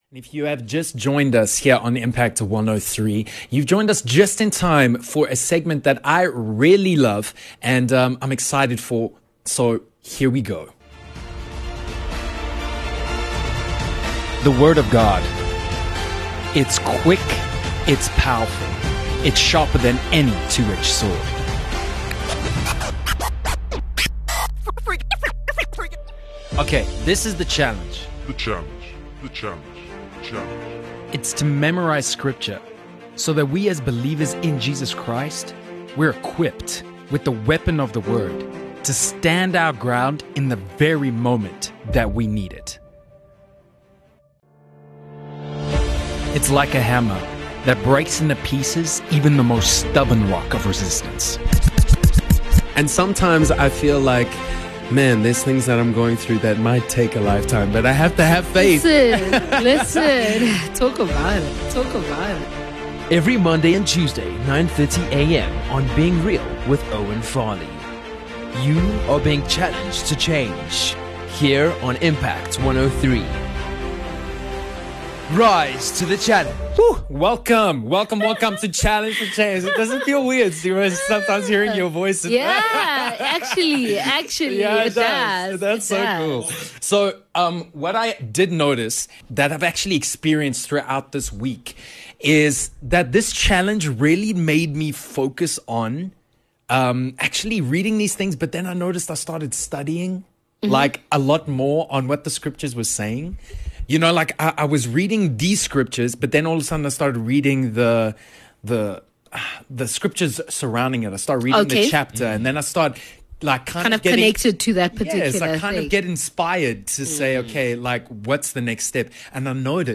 Filled with laughs, testimonies and the Word of God.